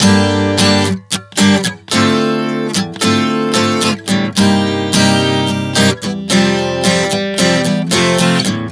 Prog 110 Gm-Dm-Cm-F.wav